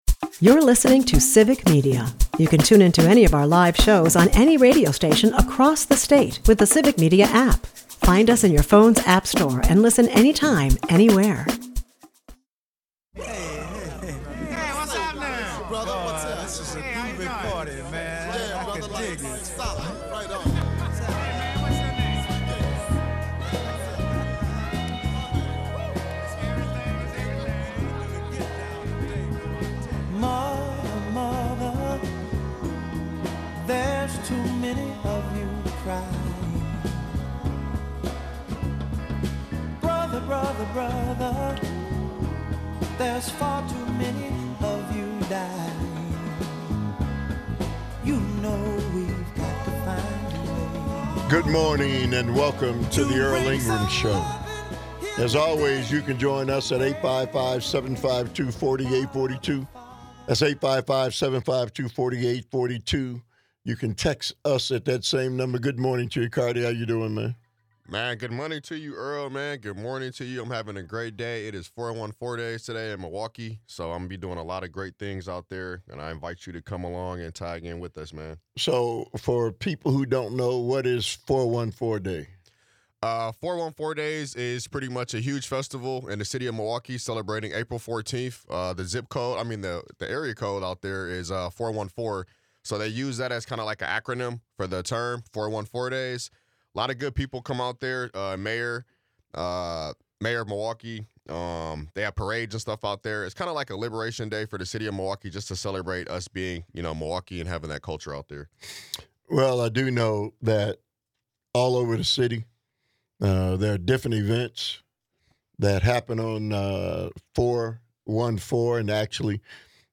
Listeners voice their anger over the cancellation of his show, highlighting the critical need for perspectives like his during these challenging times. The episode concludes with a powerful call for unity and awareness in the face of economic and social threats, reminding us that remaining silent equates to complicity.